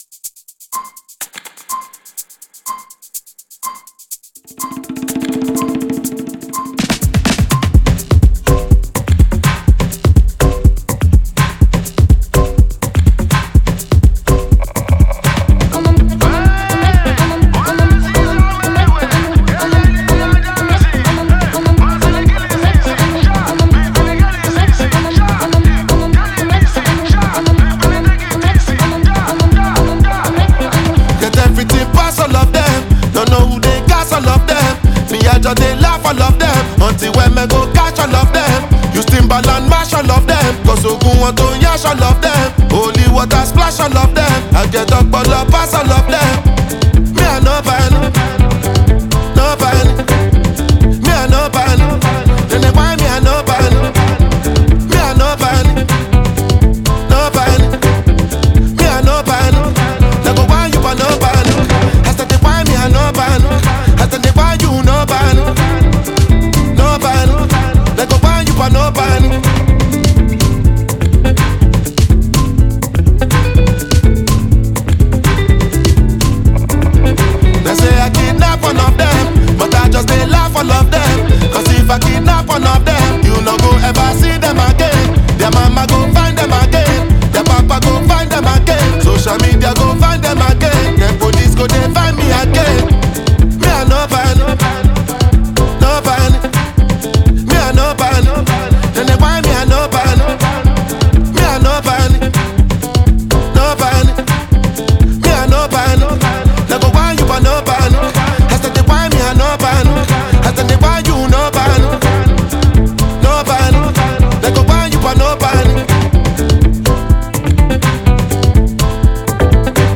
Nigerian Afrobeats